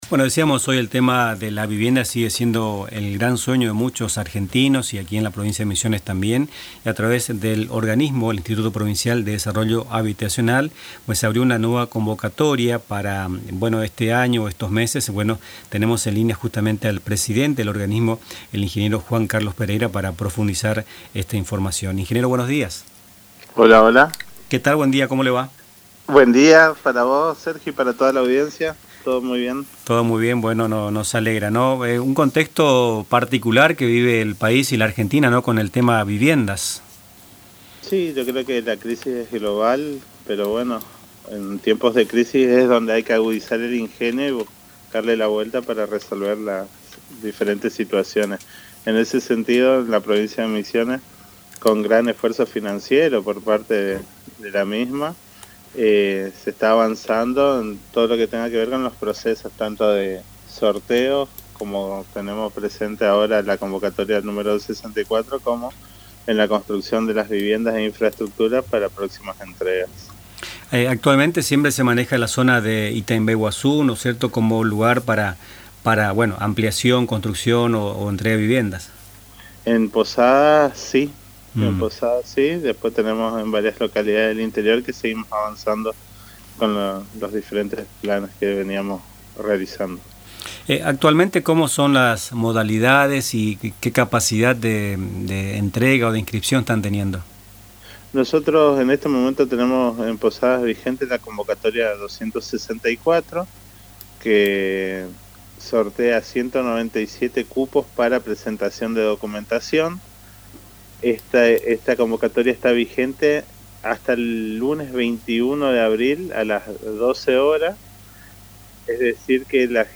En «Nuestras Mañanas», entrevistamos al presidente del IPRODHA, Ing. Juan Carlos Pereira, quién detalló la convocatoria 264 que tiene un cupo de 197 viviendas, y que a la fecha lleva inscripto a 4530 personas.